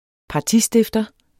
Udtale [ pɑˈti- ]